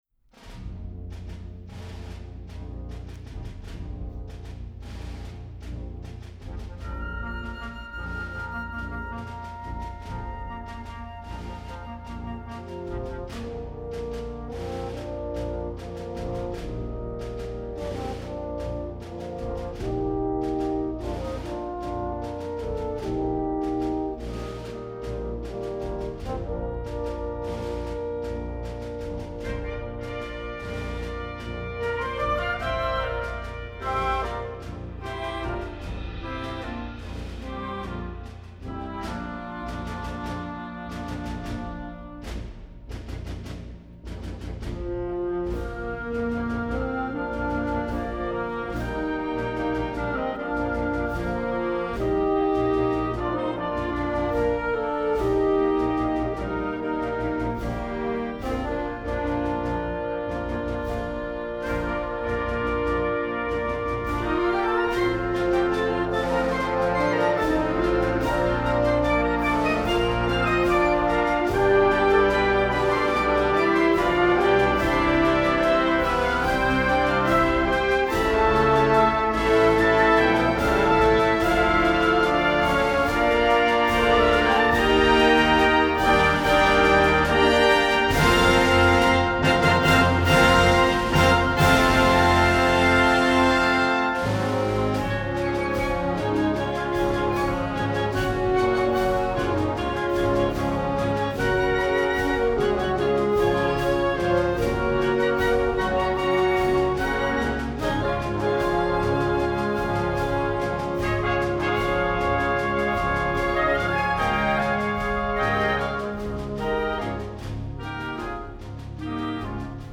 instructional, american